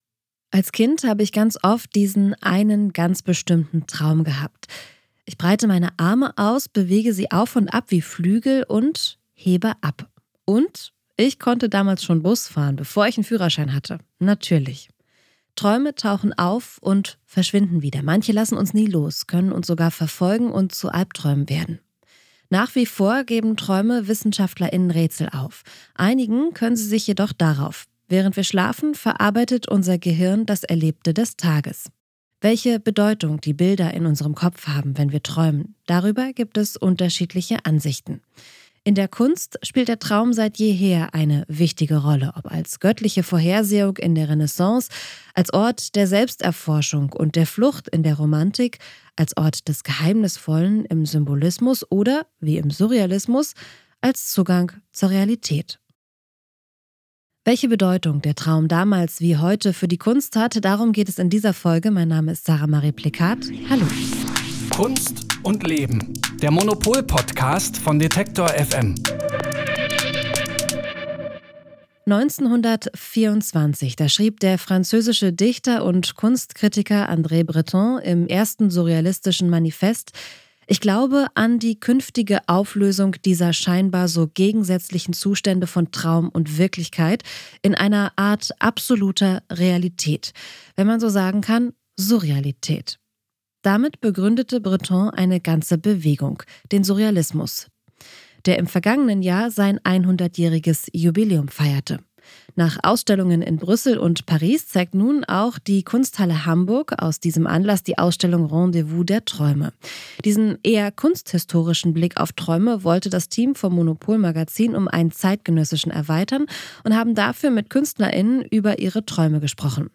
Ein Gespräch über Fliegenpilze und Züge.